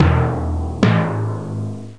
timpani.mp3